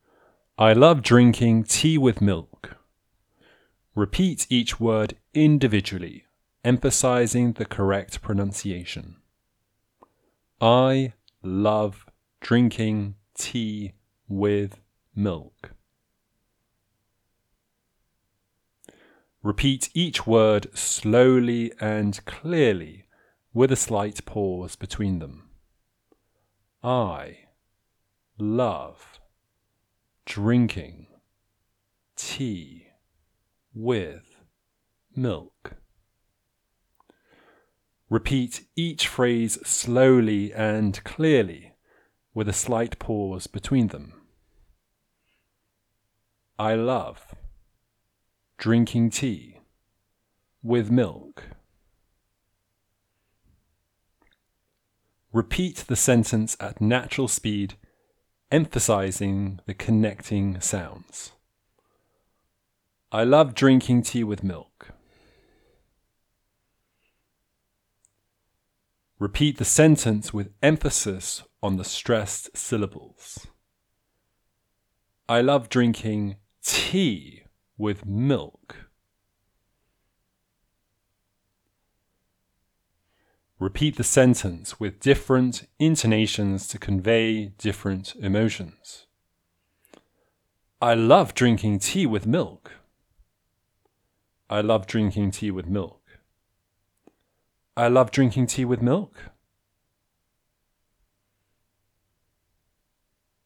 Shadowing Exercises for Improved Fluency - British English Pronunciation RP Online Courses
Repeat each word individually, emphasizing the correct pronunciation: